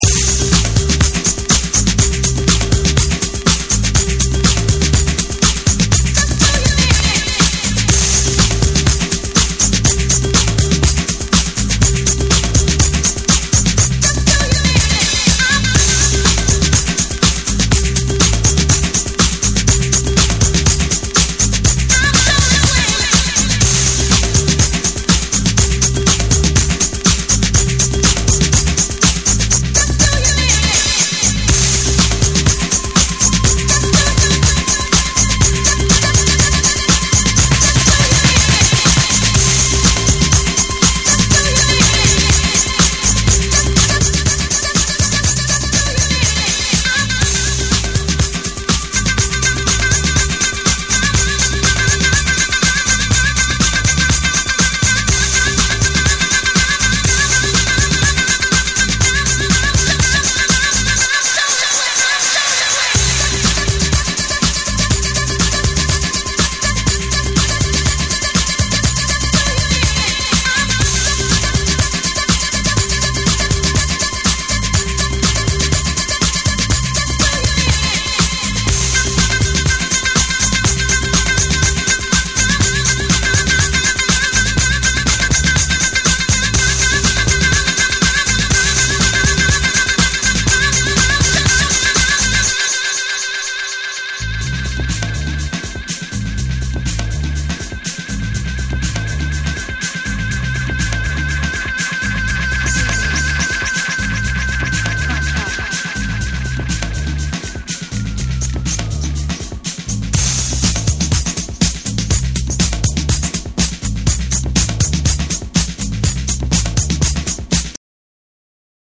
ミニマルなパーカッションにエフェクト処理がじわじわ効いてくるサイケ〜ダビーな展開、ブロークン気味のグルーヴが最高です！
TECHNO & HOUSE / DISCO DUB